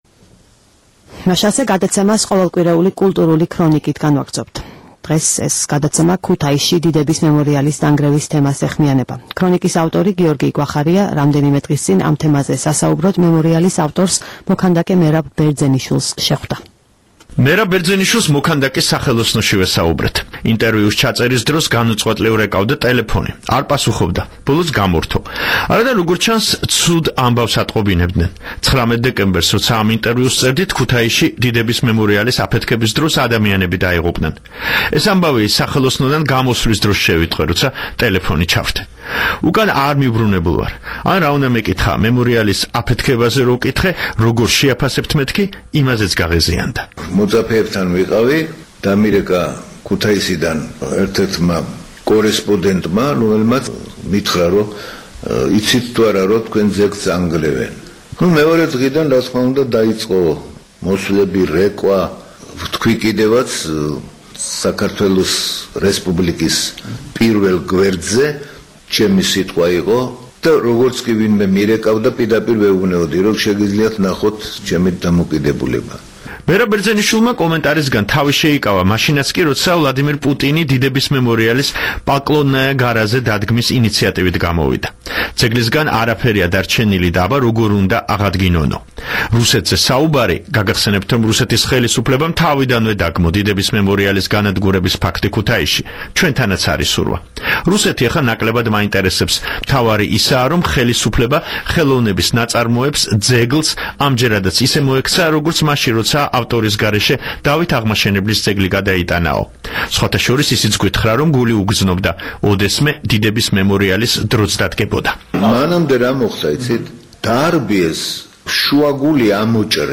საუბარი მერაბ ბერძენიშვილთან
მერაბ ბერძენიშვილს მოქანდაკის სახელოსნოში ვესაუბრეთ. ინტერვიუს ჩაწერის დროს განუწყვეტლივ რეკავდა ტელეფონი... არ პასუხობდა. ბოლოს გამორთო. არადა, როგორც ჩანს, ცუდ ამბავს ატყობინებდნენ - 19 დეკემბერს, როცა ამ ინტერვიუს ვწერდით, ქუთაისში, დიდების მემორიალის აფეთქების დროს, ადამიანები დაიღუპნენ... ეს ამბავი სახელოსნოდან გამოსვლის დროს შევიტყვე, როცა ტელეფონი ჩავრთე.